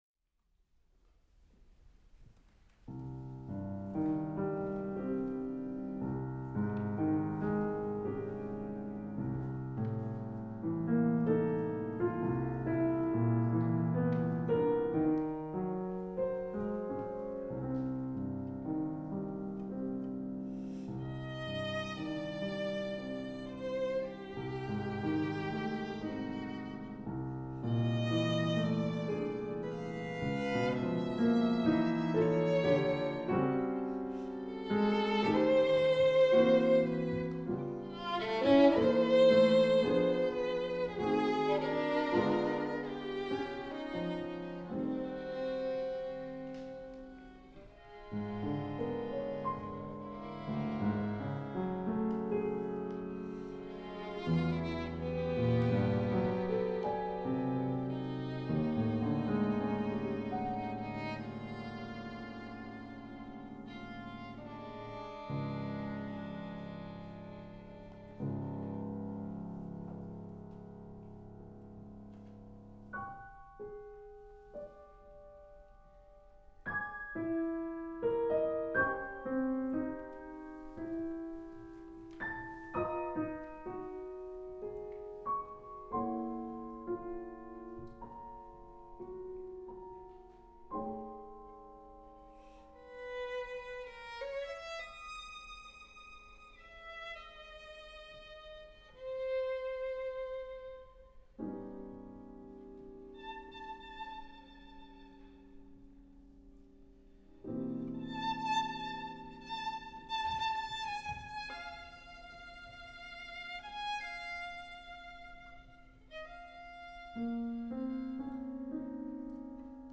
piano
Slowly and lyrically